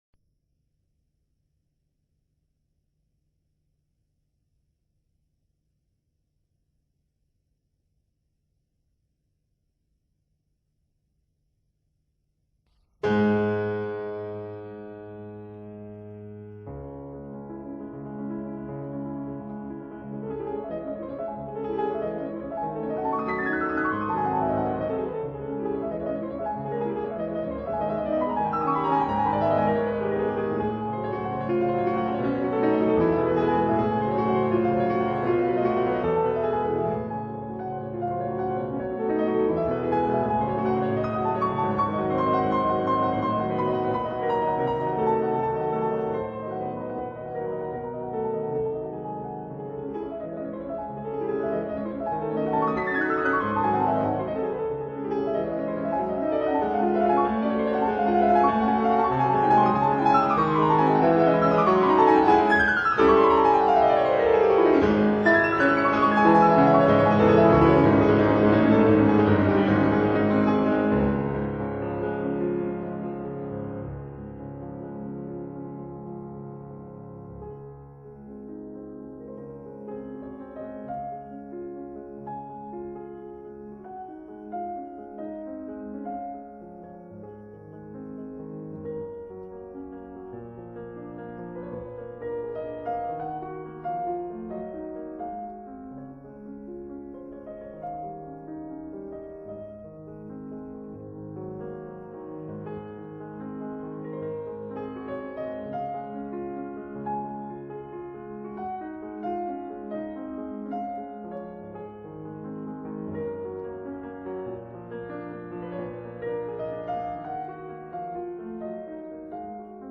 It was this piano piece that first brought me into the world of Chopin, and it was also the first time I encountered a challenge when I was practicing it in my eighth grade. This composition is in ternary form. Its difficulty lies in the continuous sextuplet of the left hand misaligning with the quick quadruplet of the right hand in the first and third movements, which is in C-Sharp minor.
The middle movement is in D-flat major and a much slower tempo with a lyrical melody, which brings the listener into a beautiful world of fantasy. The melody ending is repeated in the bass as if the world in the fantasy is still fading.
Yundi_Li_-_Chopin_Fantasie_Improm_.mp3